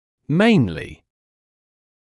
[‘meɪnlɪ][‘мэйнли]главным образом, в основном